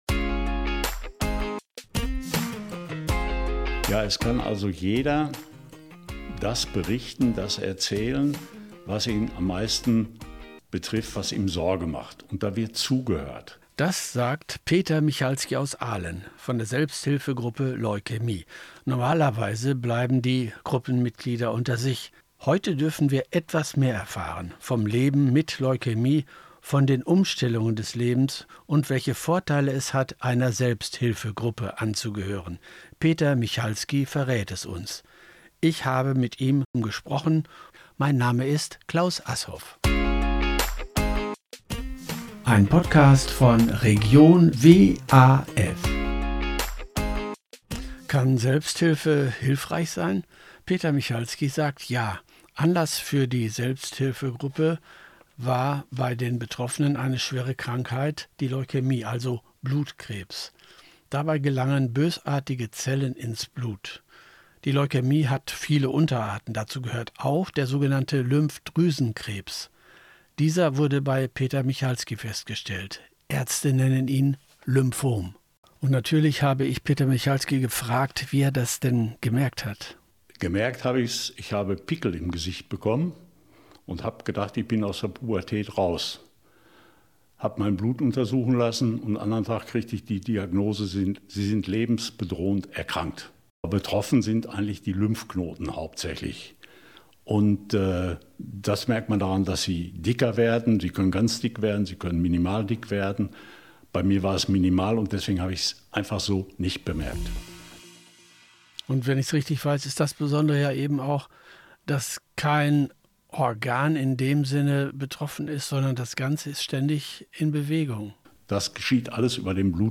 Radio-Interview mit Radio WAF zum Thema Selbsthilfe